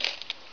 coins.wav